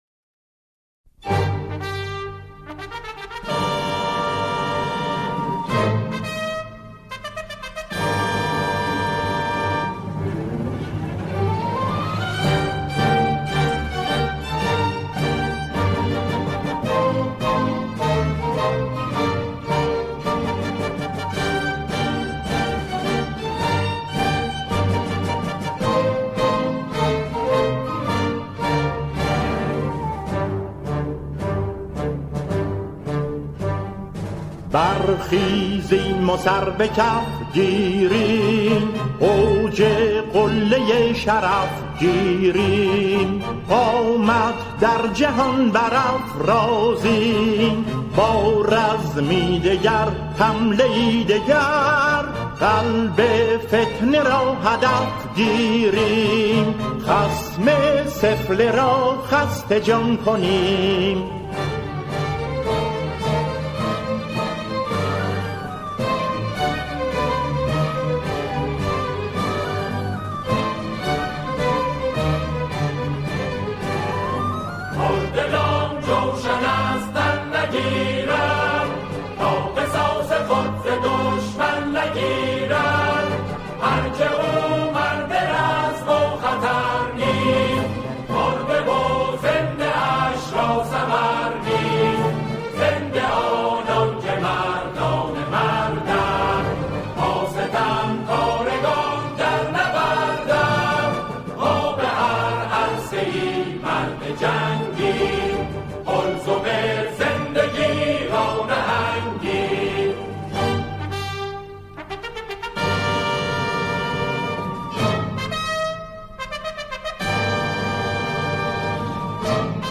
گروه کر